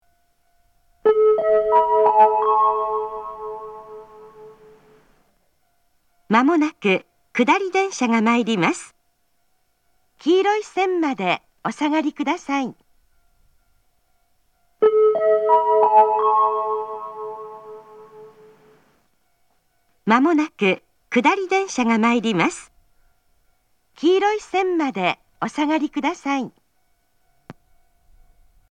仙石旧型（女性）
接近放送
仙石旧型女性の接近放送です。同じ内容を2度繰り返します。